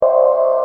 qbuzz.mp3